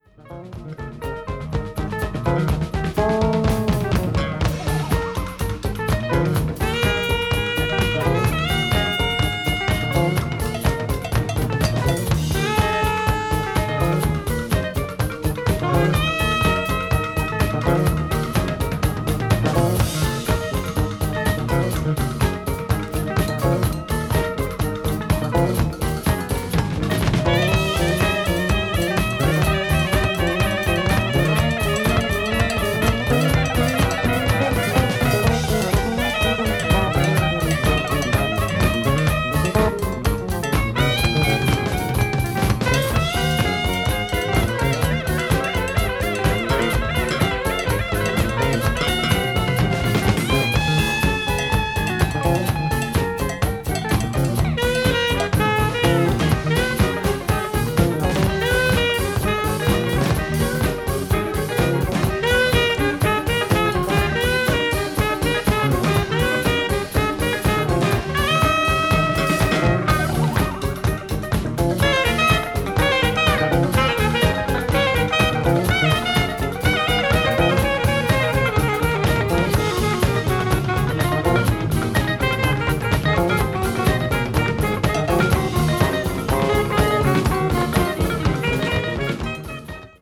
media : EX+/EX+(some slightly noises.)
captures the recording of a live performance
avant-jazz   free jazz   jazz funk   new wave